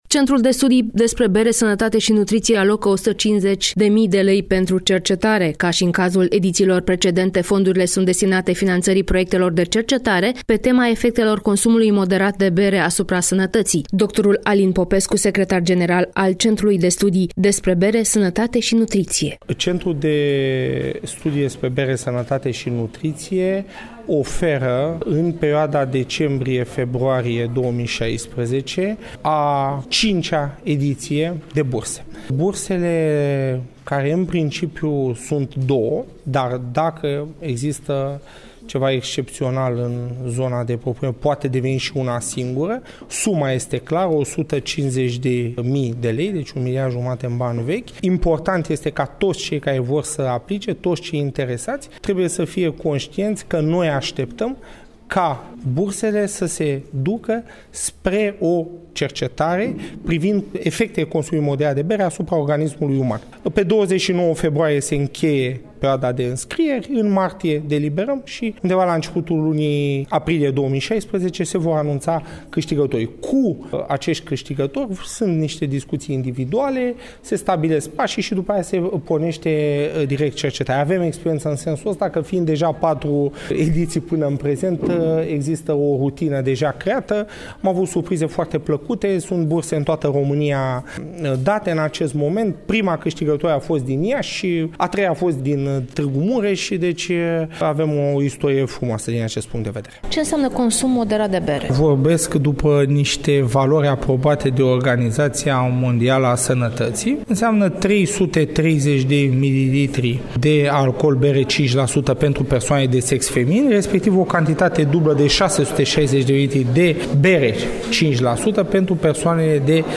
(INTERVIU) Burse de cercetare privind efectele consumului moderat de bere asupra organismului